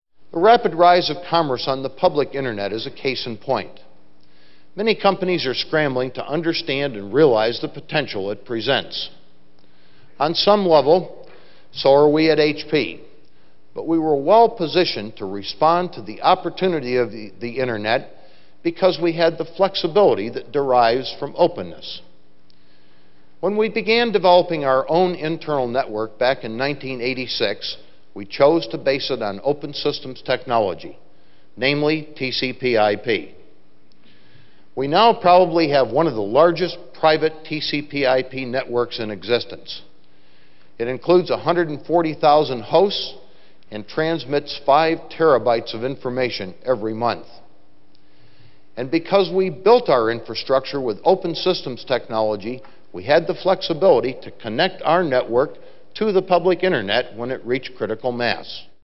HP: Lew Platt Keynote Address from UniForum '96